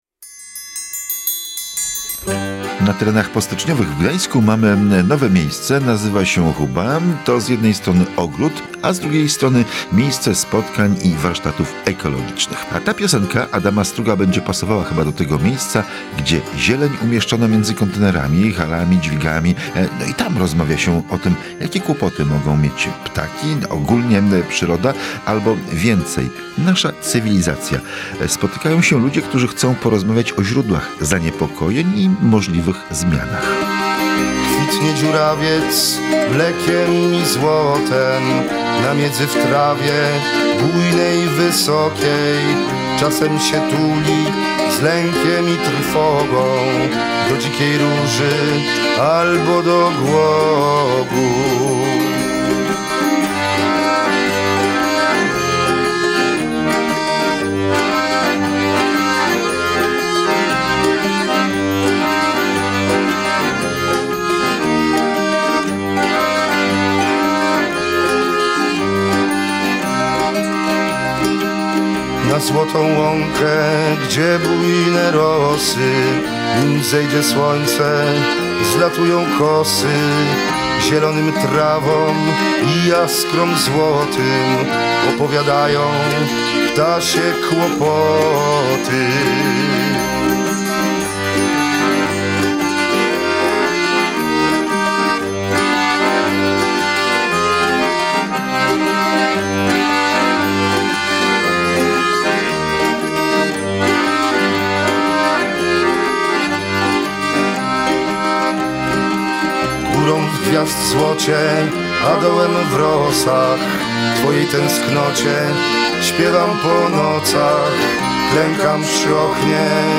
Uczestnicy wydarzenia cicho czytają rozdane fragmenty książki, niebawem zacznie się dyskusja